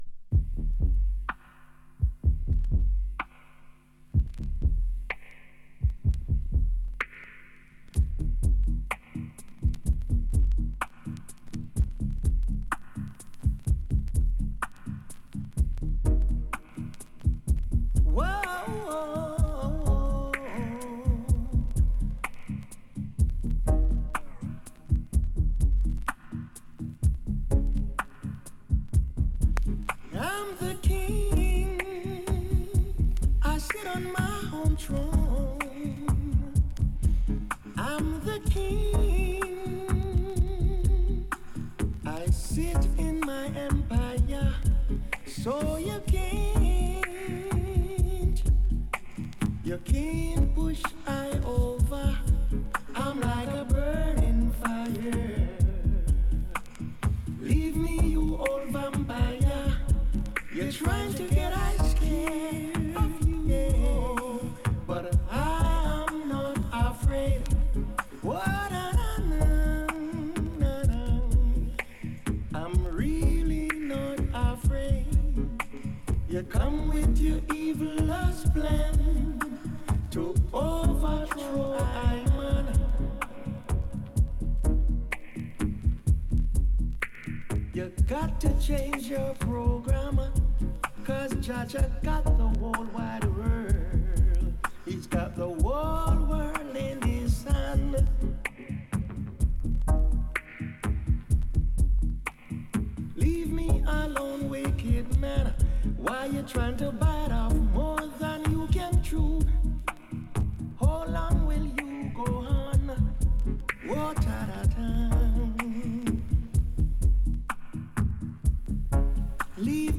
Reggae, Hip-Hop, Afro Beats & Quality Music